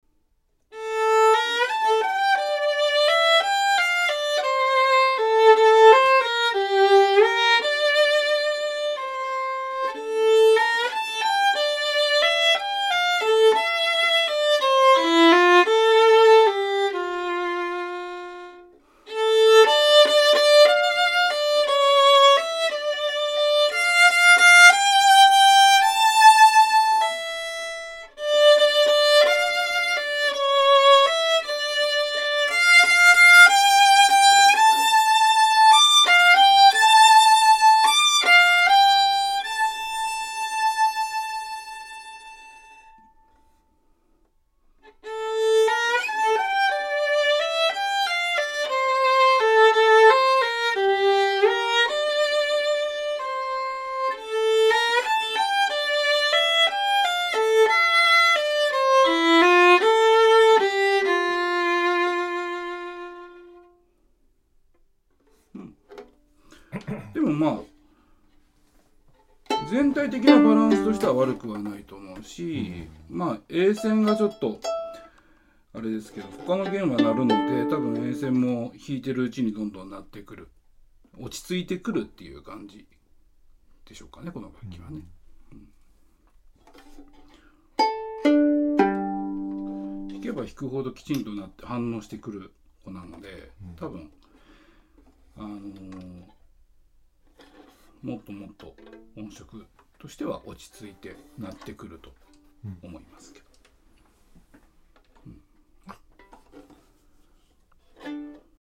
中々パワフルな個体で気持ち良く鳴ってくれます。
ドイツらしくしっかりとした丁寧な作りで、バランスの良い鳴りです。
音質：高温域は明るくて・明確で・きれいで・またエネルギッシュです。 より低音域は深くて・強くて・またパワフルです。